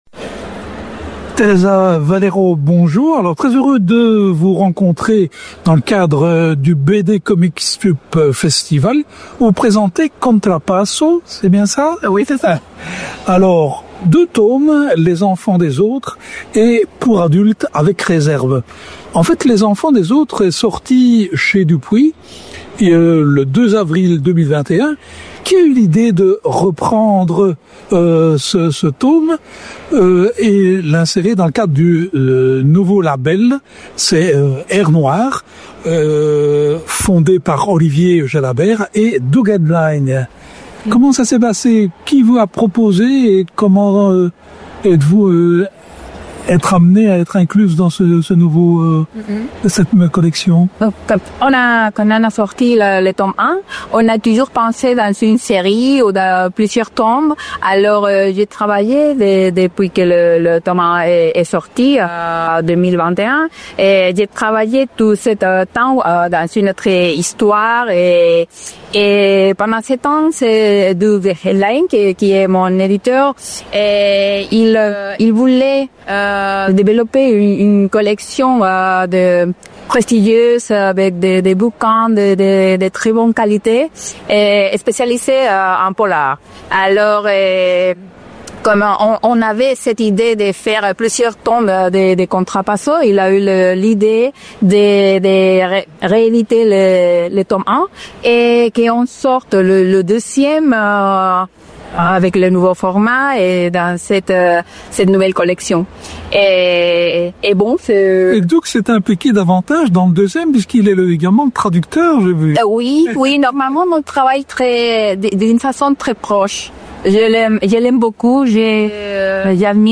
Rencontre avec la créatrice.